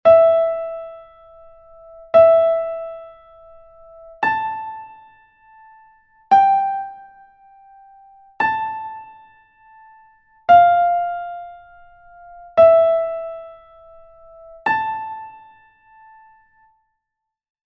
Listen to the sound files to recognize the notes High D, High E, High F, High G, and High A . You will be given the first note of each exercise to make it easier.